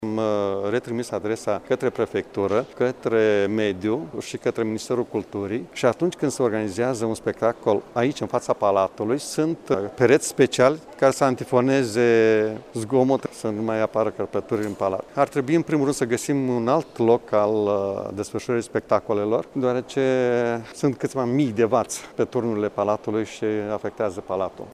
Preşedintele Consiliului Judeţean Iaşi, Maricel Popa susţine că ar fi de preferat ca în Piaţa Palatului să nu mai fie susţinute concerte sau dacă acest lucru nu poate fi evitat, să fie montaţi pereţi de antifonare: